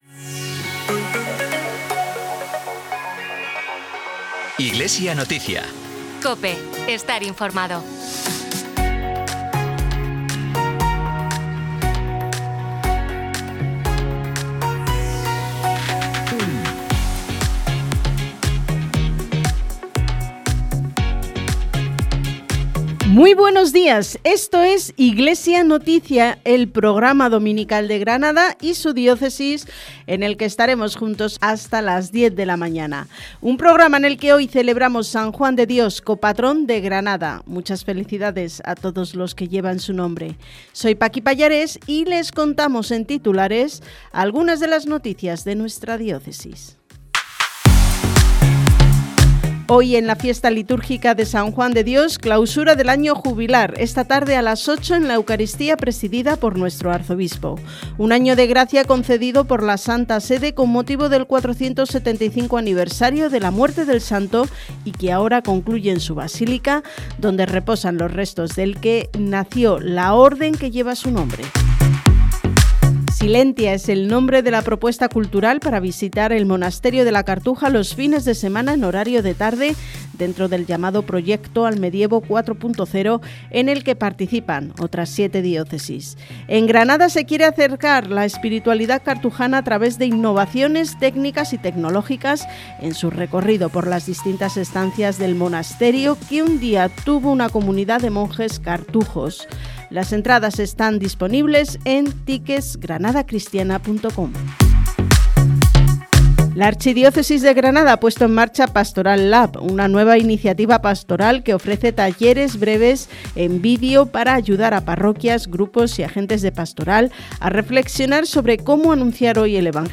Programa emitido en COPE Granada y COPE Motril el 8 de marzo de 2026.